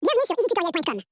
2. Changer la vitesse et la hauteur de l'audio
audio-change-hauteur-et-vitesse.mp3